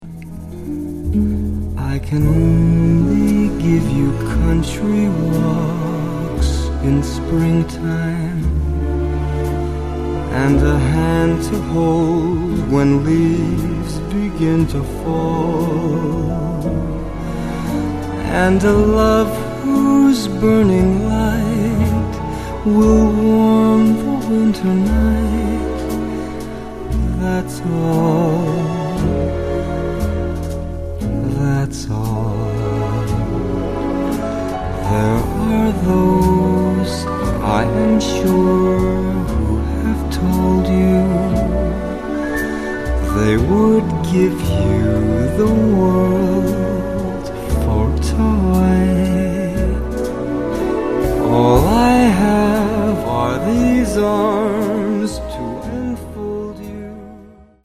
standards jazz...